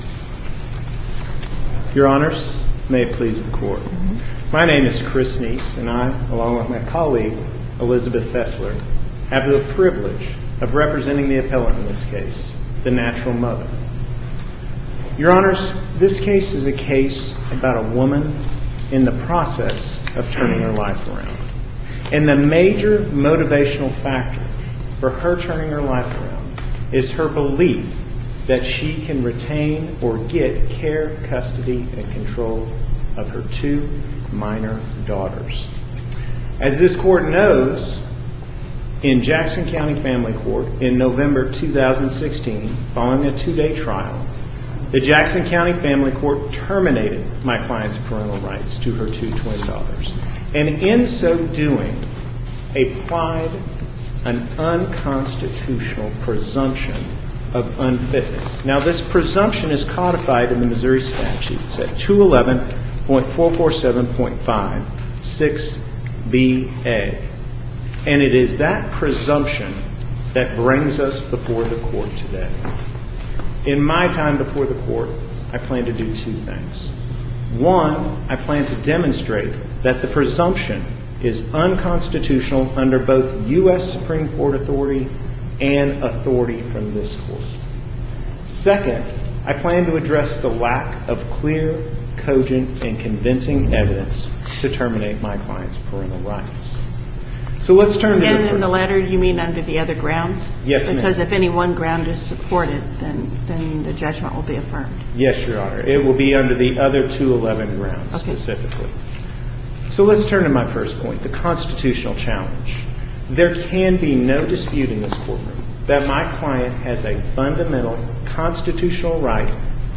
MP3 audio file of arguments in SC96376